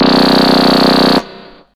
SYNTHBASS4-L.wav